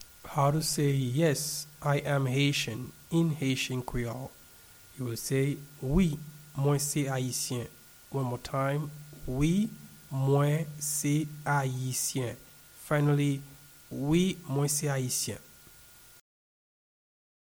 Yes-I-am-Haitian-in-Haitian-Creole-Wi-mwen-se-ayisyen-pronunciation.mp3